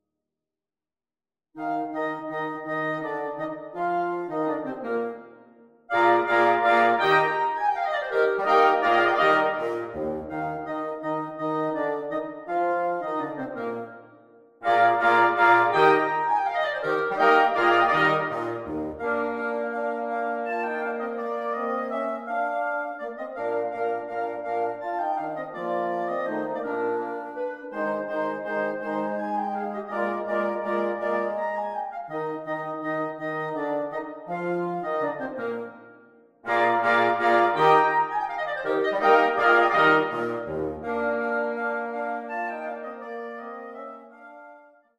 2 oboes, 2 clarinets, 2 horns, 2 bassoons